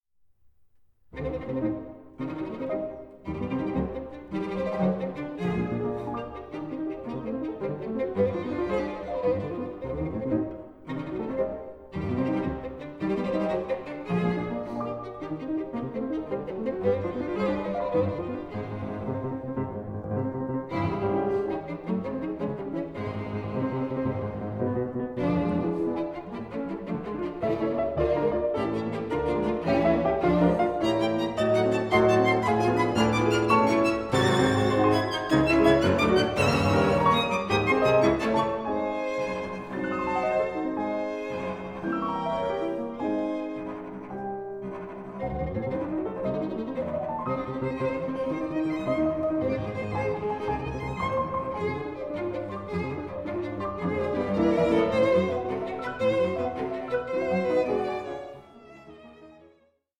Scherzo 04:24